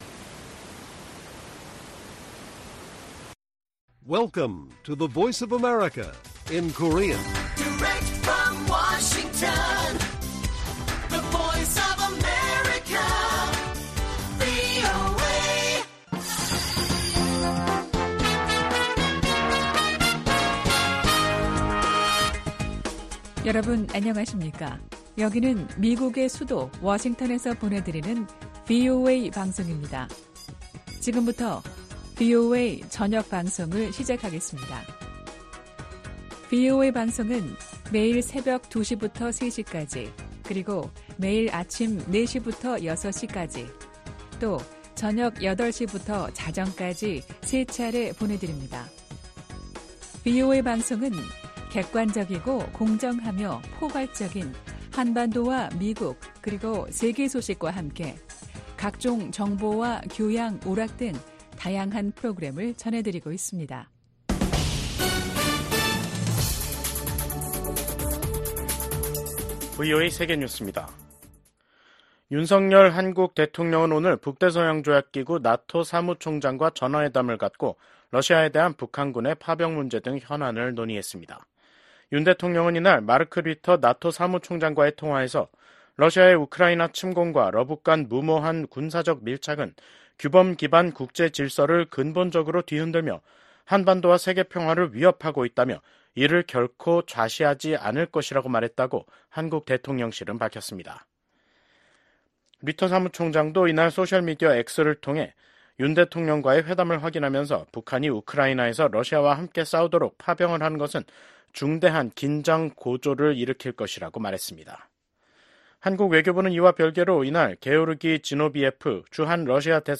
VOA 한국어 간판 뉴스 프로그램 '뉴스 투데이', 2024년 10월 21일 1부 방송입니다. 북한이 대규모 병력을 우크라이나 전장에 투입하기로 했다는 한국 정부의 발표에 대해 미국 정부가 중대한 우려의 입장을 밝혔습니다. 북한에 인력을 요청할 수밖에 없다면 이는 러시아의 절망의 신호일 것이라고 지적했습니다.